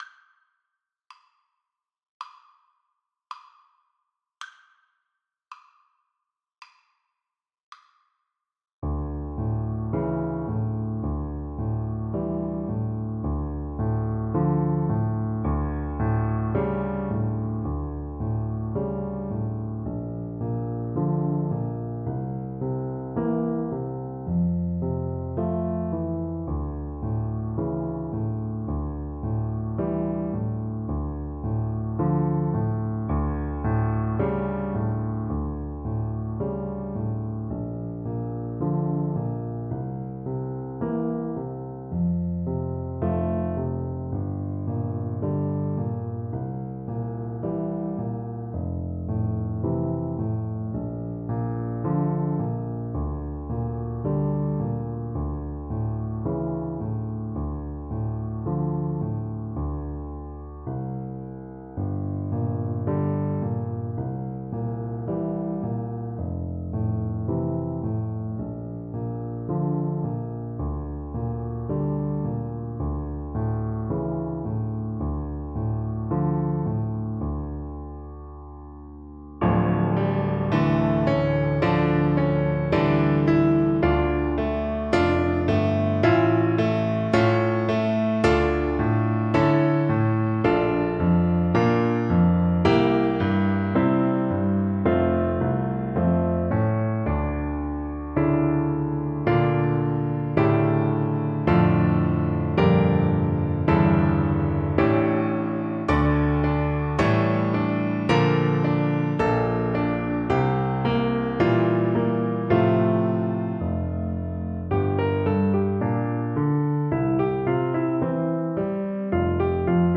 French Horn
D minor (Sounding Pitch) A minor (French Horn in F) (View more D minor Music for French Horn )
Slow and stately = 40
2/2 (View more 2/2 Music)
Traditional (View more Traditional French Horn Music)
world (View more world French Horn Music)
Finnish